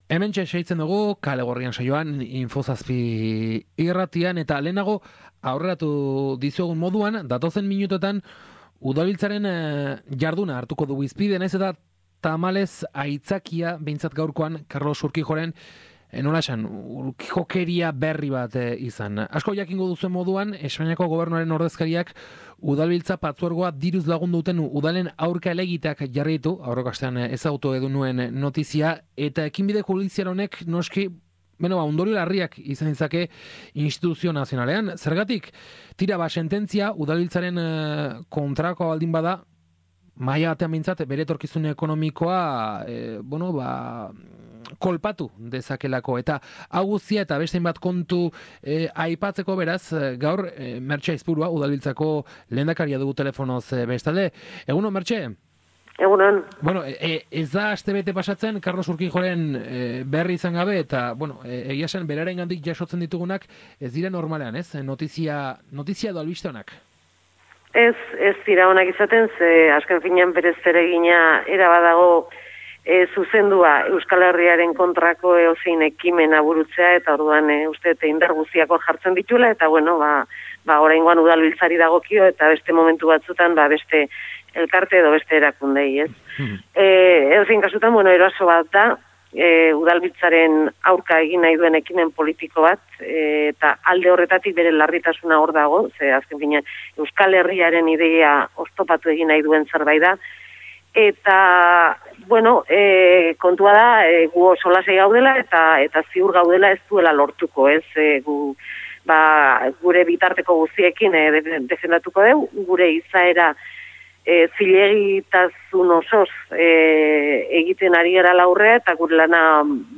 Bisitan, ikastolarentzako 10.000 euroko diru laguntza iragarri du Mertxe Aizpuruak eta Kaskaroteneak Udalbiltzaren babes osoa duela baieztatu du. Horri buruz, eta bestelako gaieie buruz galdetu diogu Kalegorrian saioan, Mertxe Aizpuruari.